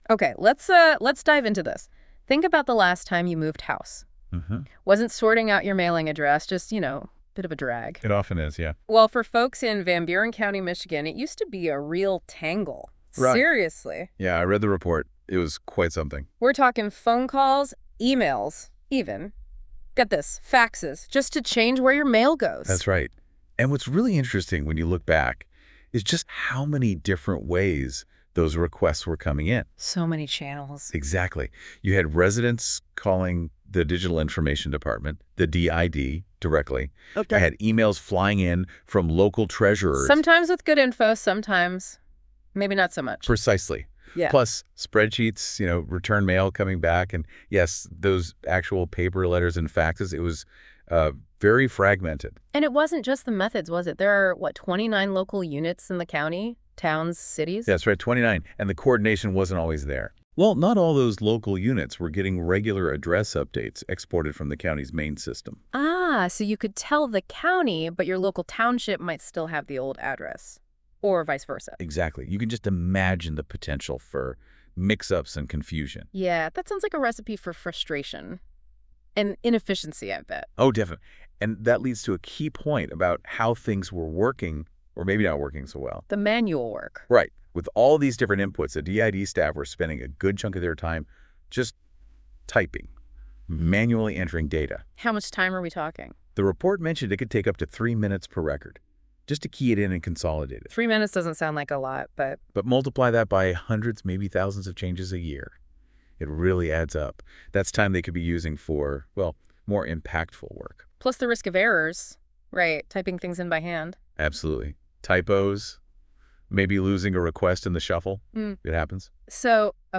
Host: AI Generated by NotebookLM